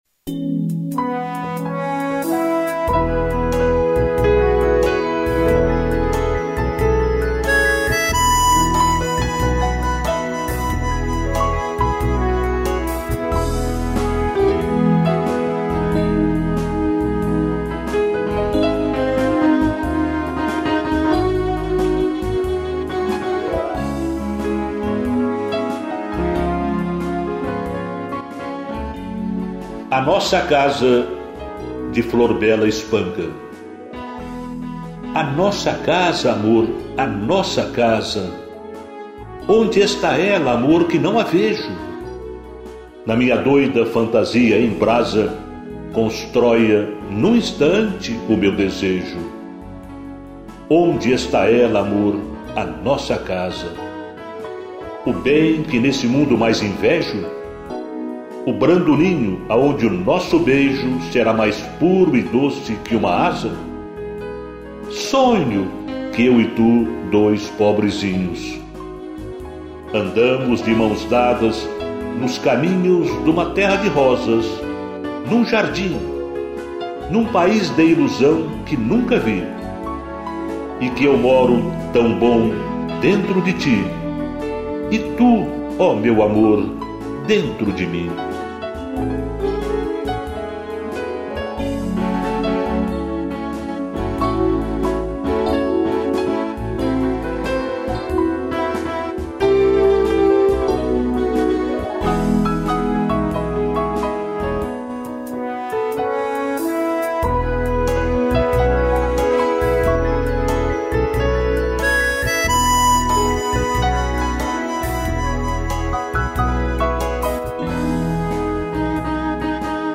piano e trompa